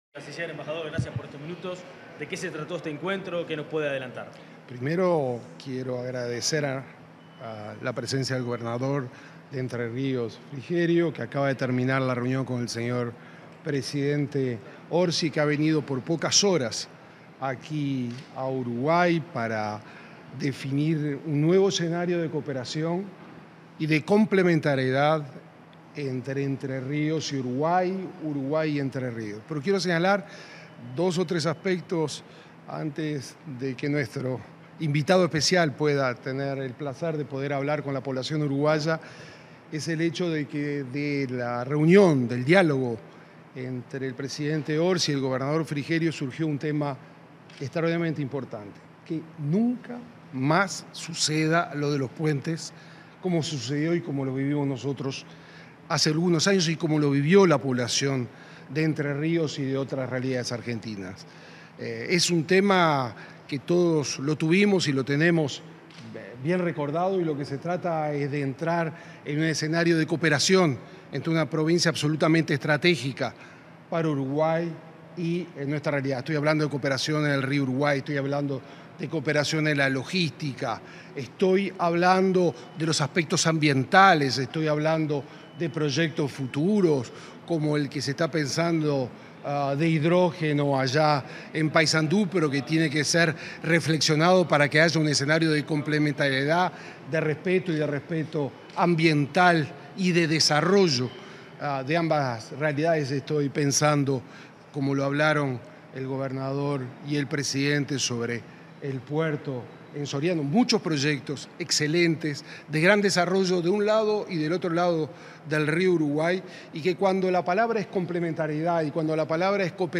Declaraciones a la prensa del canciller uruguayo y del gobernador de Entre Ríos
Declaraciones a la prensa del canciller uruguayo y del gobernador de Entre Ríos 27/03/2025 Compartir Facebook X Copiar enlace WhatsApp LinkedIn Tras el encuentro mantenido con el presidente de la República, profesor Yamandú Orsi, este 27 de marzo, el ministro de Relaciones Exteriores, Mario Lubetkin, y el gobernador de la provincia de Entre Ríos, Rogelio Frigerio, realizaron declaraciones a la prensa.